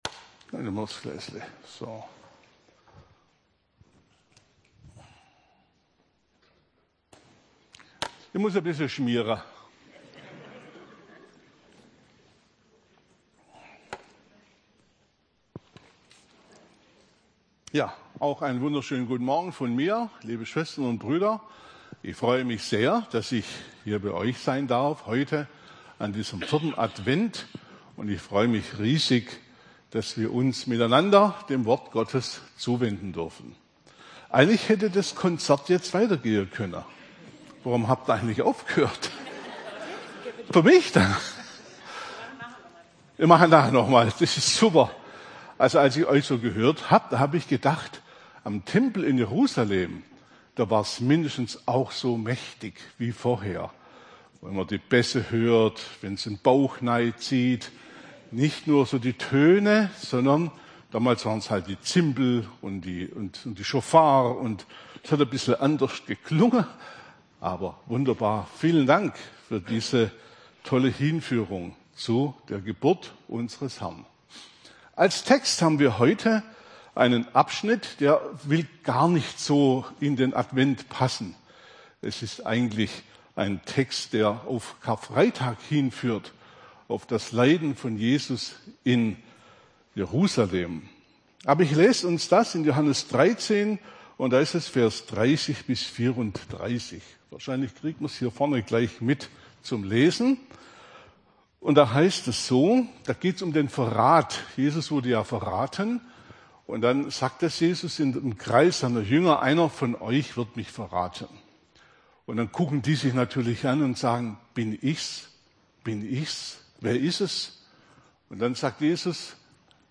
Vormittagsgottesdienst zum Thema: Joh 13,30-35 beim Christusbund Kirchheim unter Teck.